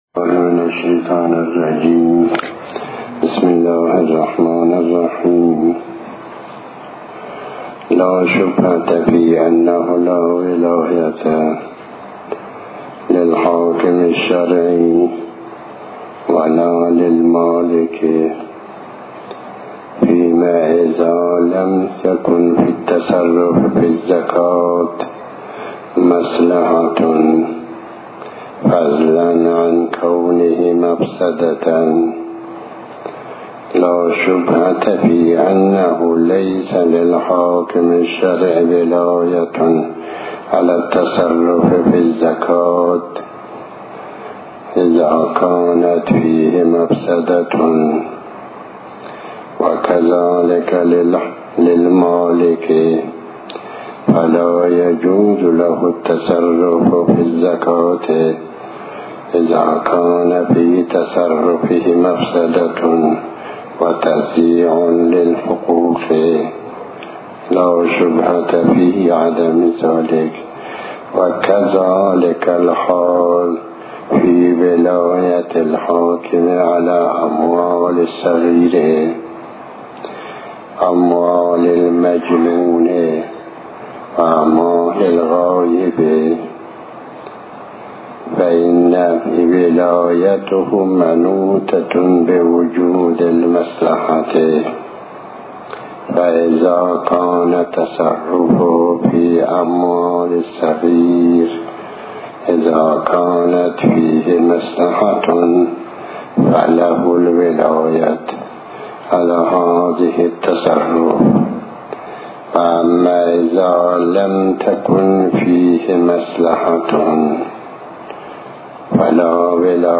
تحمیل آیةالله الشيخ محمداسحاق الفیاض بحث الفقه 38/01/24 بسم الله الرحمن الرحيم الموضوع: خــتــام.